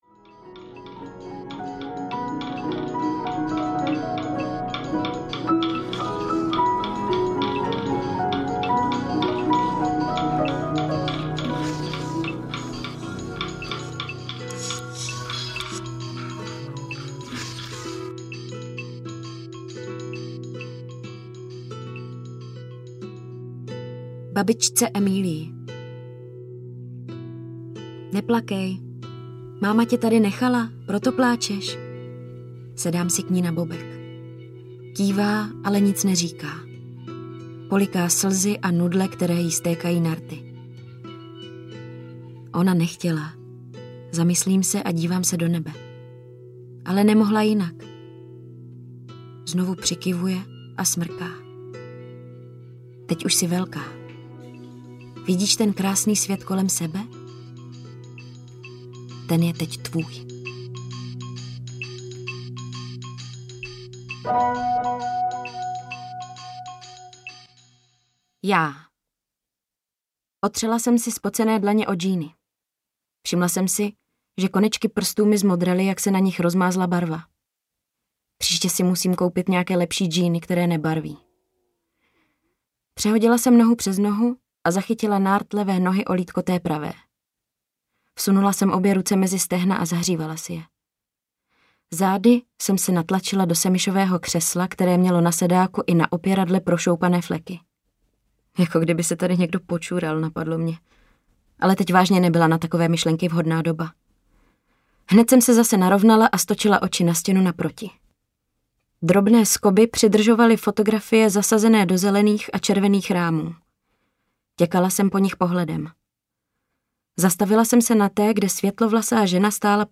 Podbrdské ženy audiokniha
Ukázka z knihy